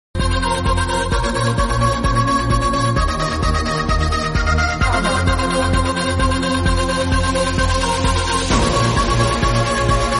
Humanoid Robot 2025 defect in sound effects free download
Humanoid Robot 2025 defect in its leg